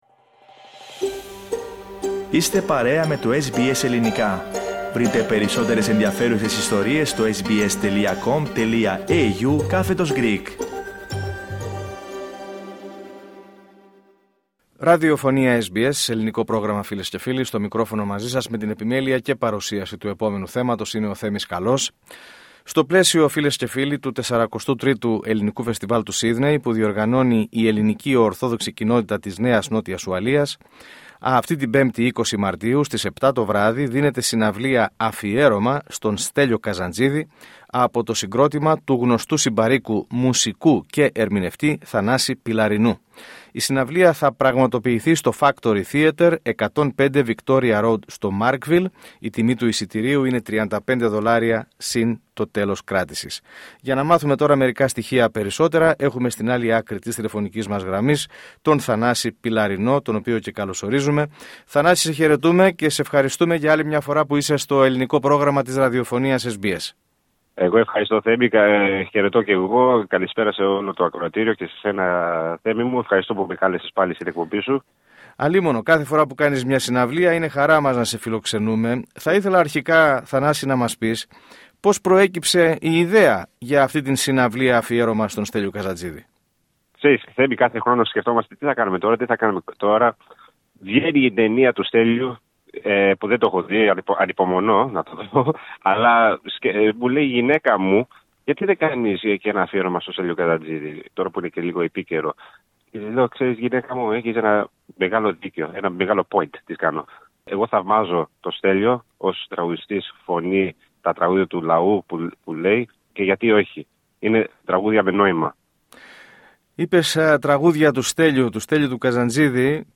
Σε συνέντευξή